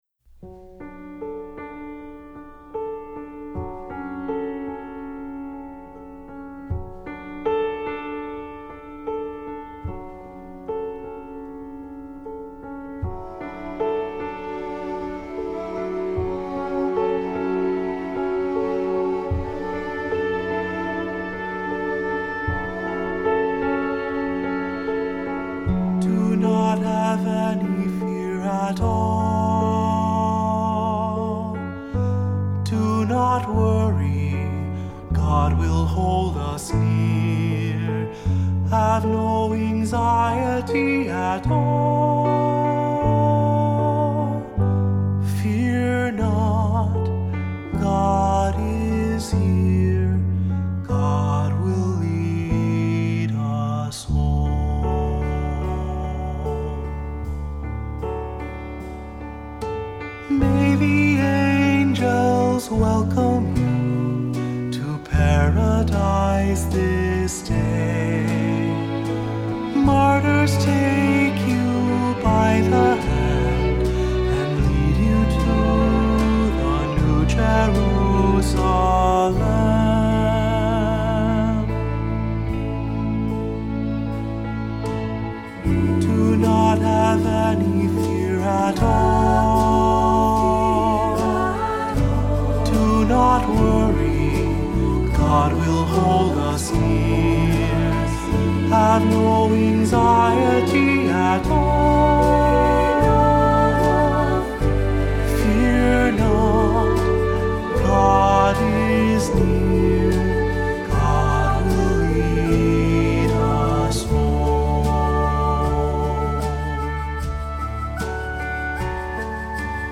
Accompaniment:      Keyboard
This tender song of farewell